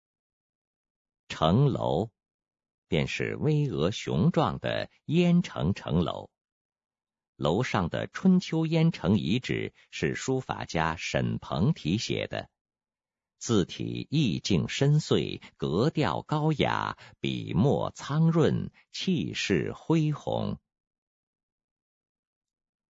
语音导览